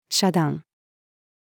遮断-female.mp3